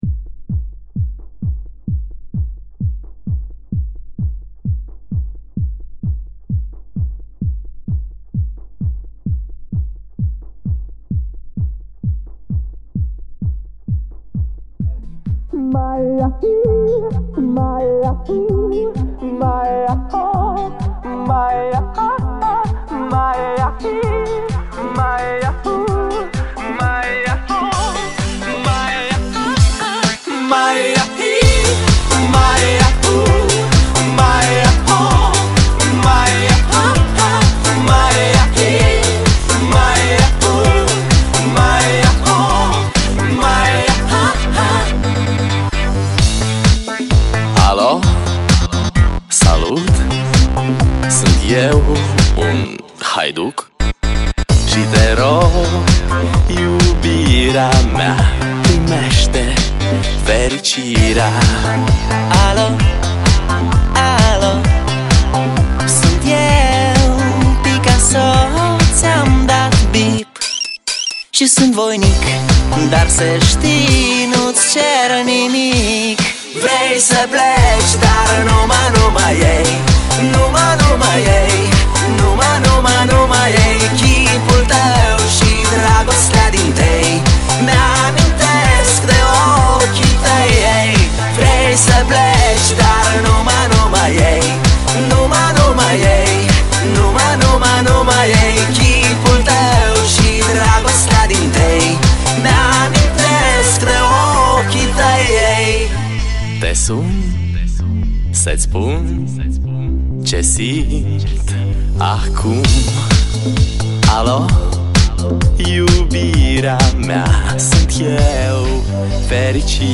法国香颂